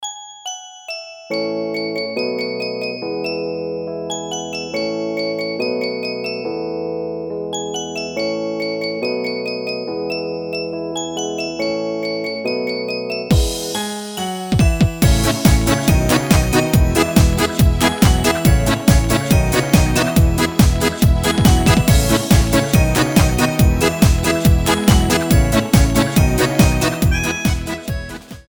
• Качество: 320, Stereo
позитивные
без слов
инструментальные
праздничные
звонкие
Красивый инструментальный проигрыш